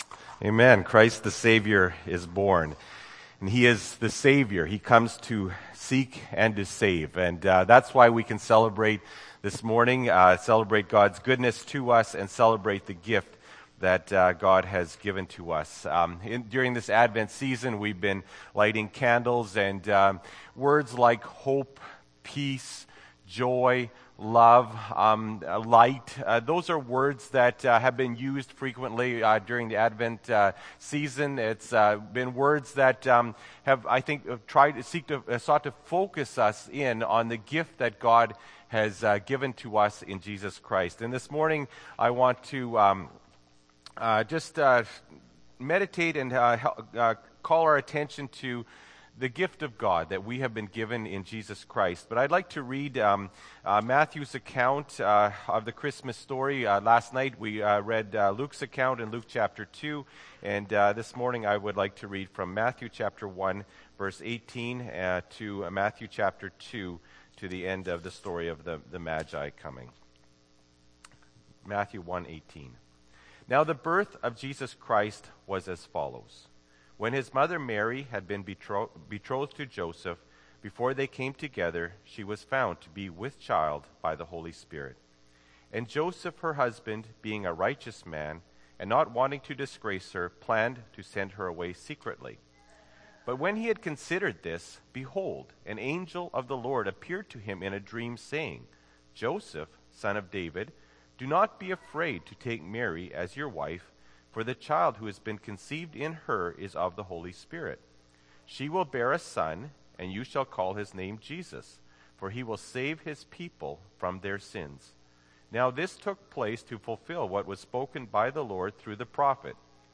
Dec. 25, 2013 – Sermon